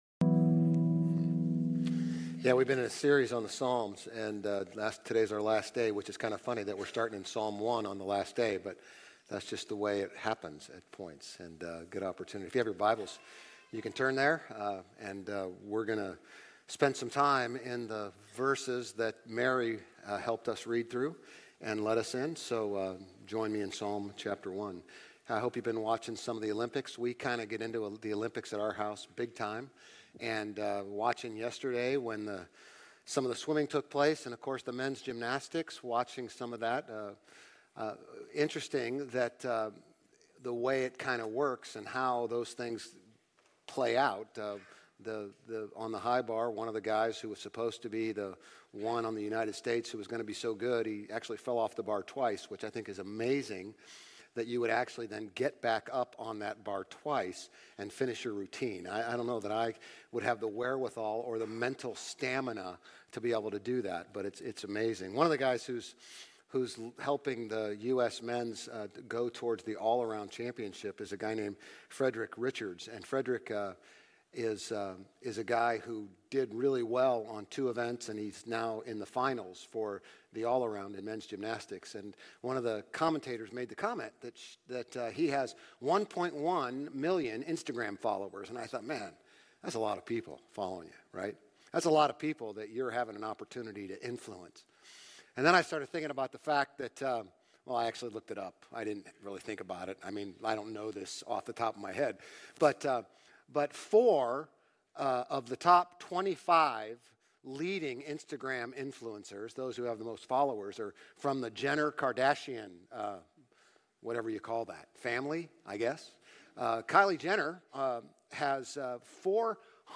Grace Community Church Old Jacksonville Campus Sermons Psalm 1 Jul 29 2024 | 00:35:34 1x 00:00 / 00:35:34 Subscribe Share RSS Feed Share Link Embed